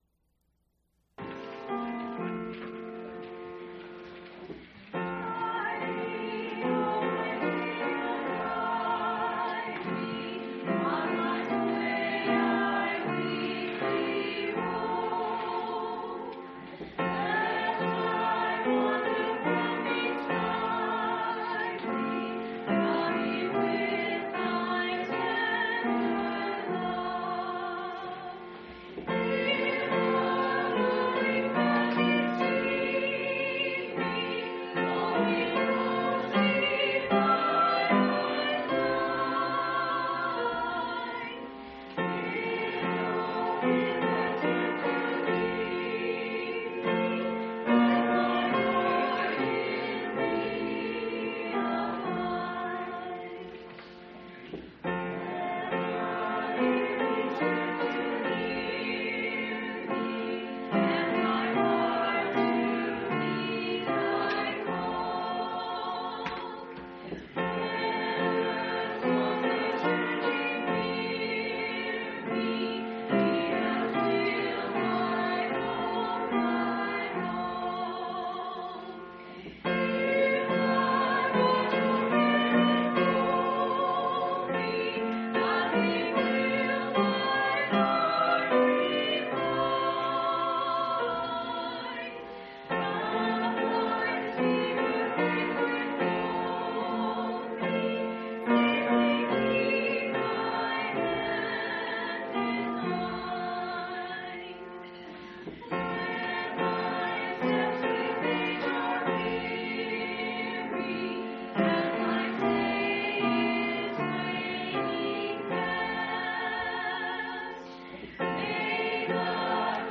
4/6/2003 Location: Phoenix Local Event
audio-sermons